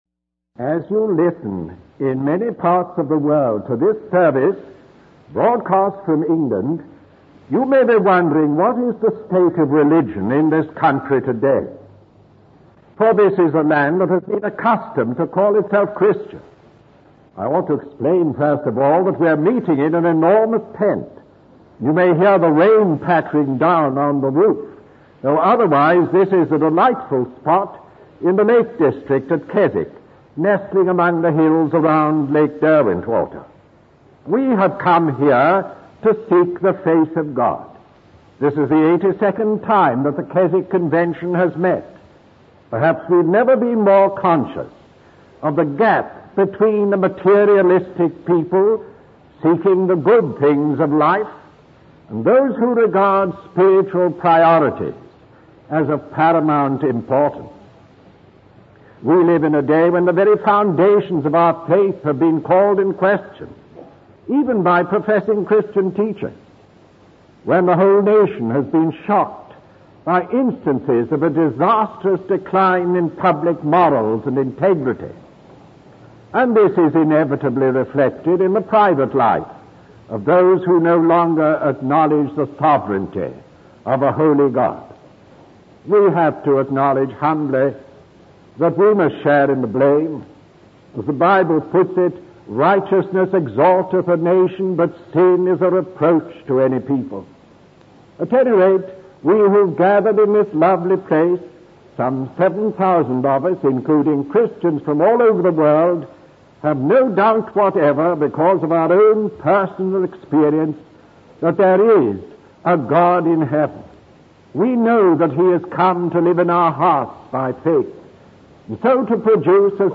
In this sermon, the speaker emphasizes that the convention is not a time for religious entertainment, but rather a time for serious reflection on one's spiritual journey.